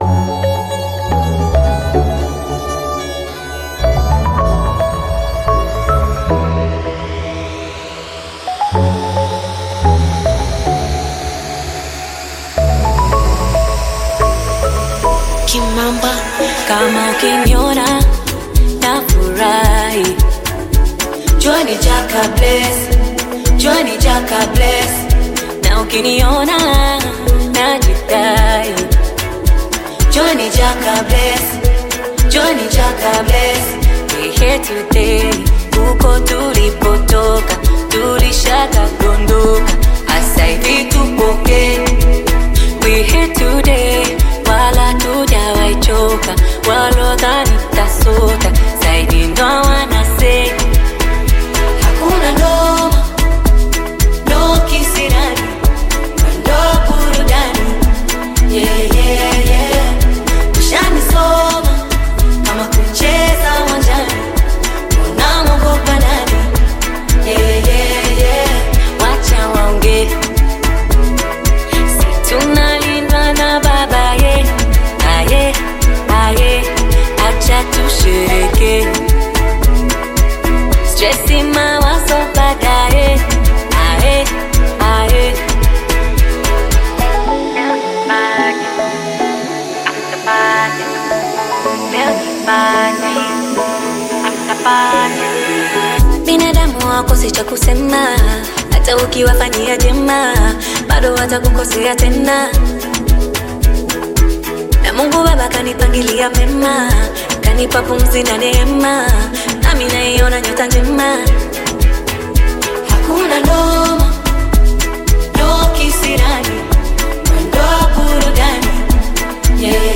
Bongo Flava music track
Tanzanian Bongo Flava